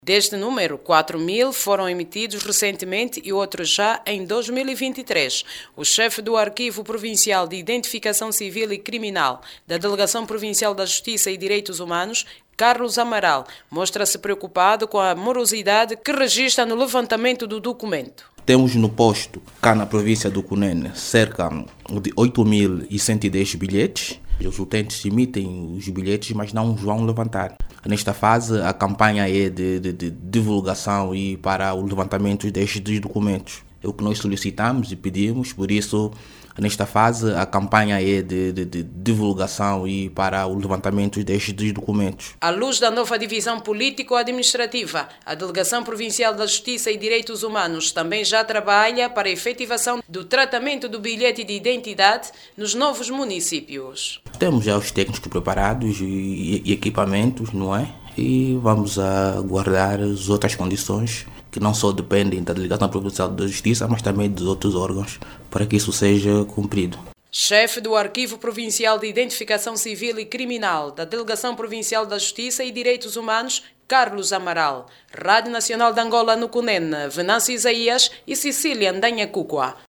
CUNENE-BILHETE-DE-IDENTIDADE-20HRS.mp3